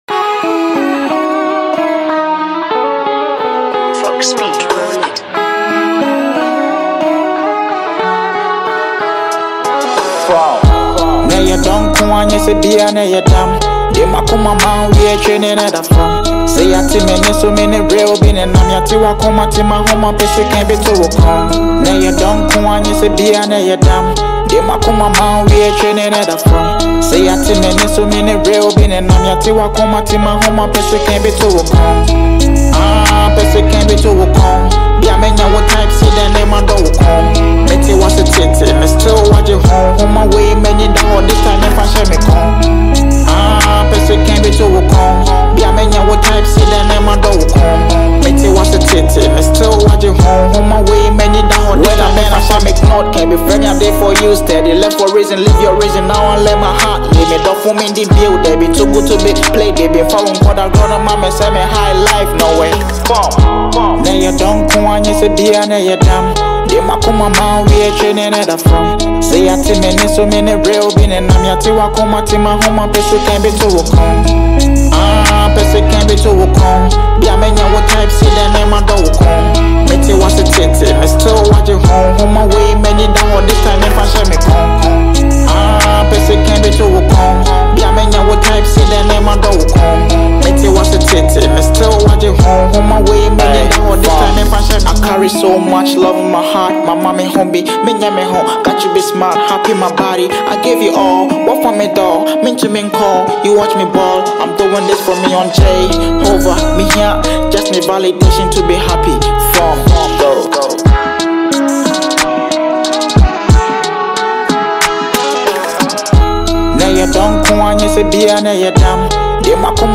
a vibrant blend of Afrobeats and highlife rhythms
With his smooth vocals and effortless flow